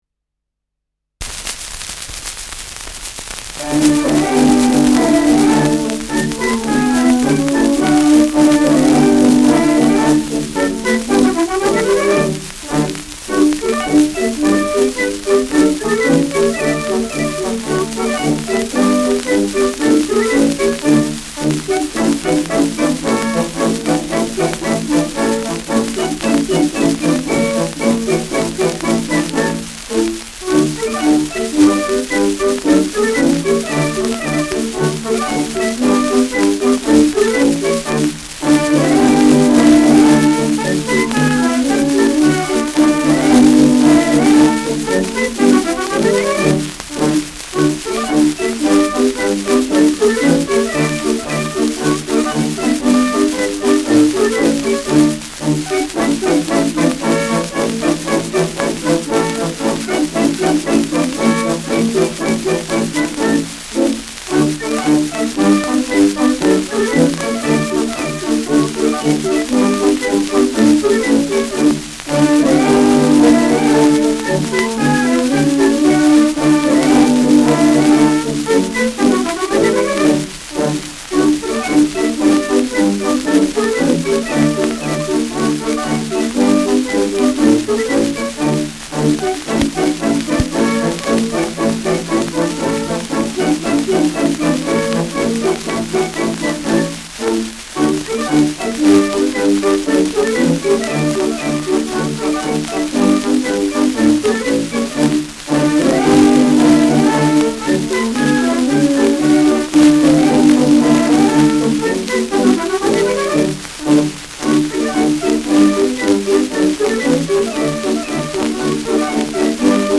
Schellackplatte
Tonrille: Abrieb : graue Rillen : Kratzer durchgängig
präsentes Rauschen
Kapelle Peuppus, München (Interpretation)